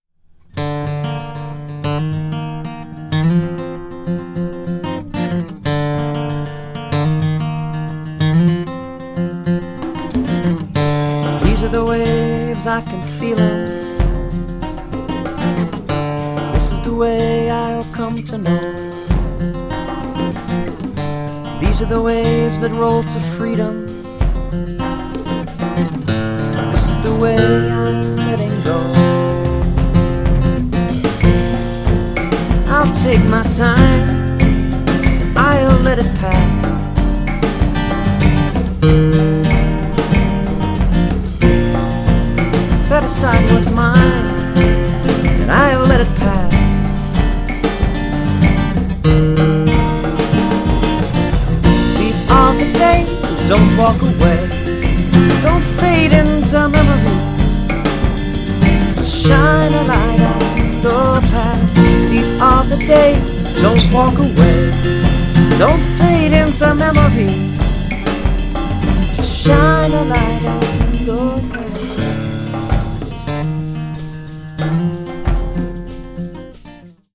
Here's a quick, rough demo of first verse and chorus:
I did a quick-&-dirty experiment with Mozambique, BonzoPak and a combo of Light&Funky and Tumbao with Jamcussion. The timing is off either because I got the BPM wrong or you didn't use a click track.